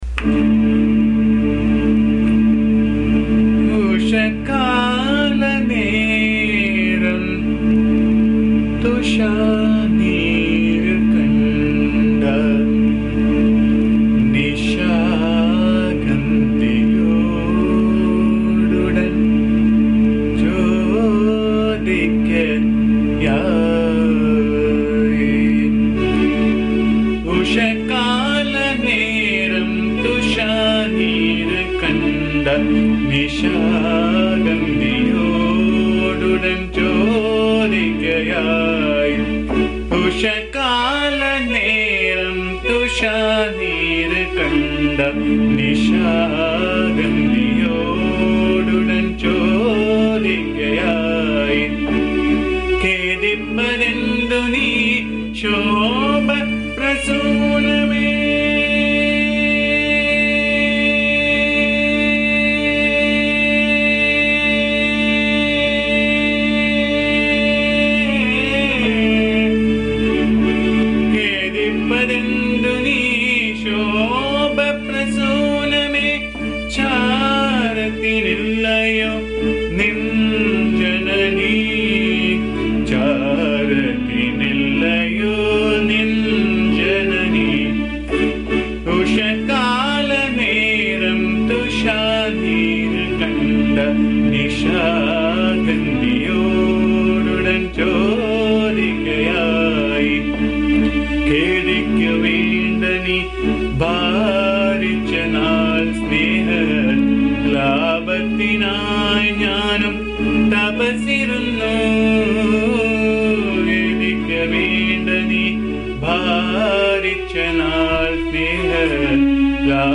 The tune is set in Raga Kalyani.
bhajan song